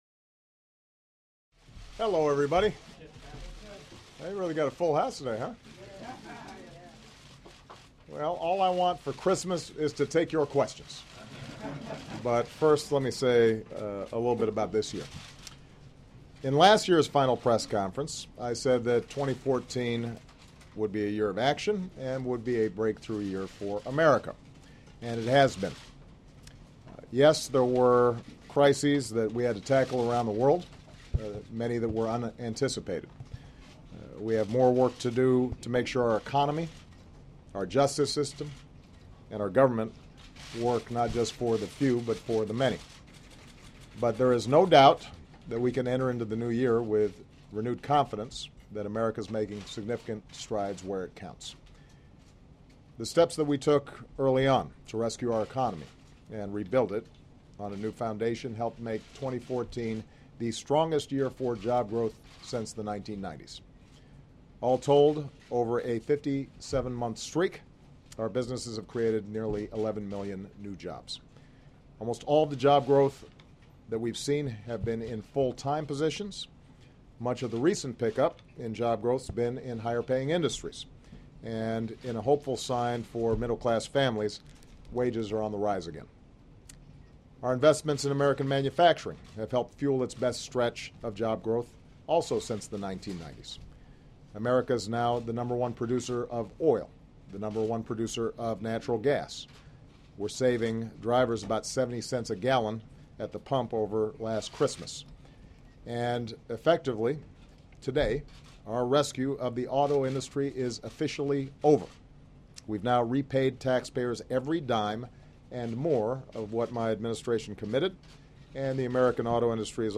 U.S. President Obama holds a year-end press conference